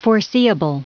Prononciation du mot foreseeable en anglais (fichier audio)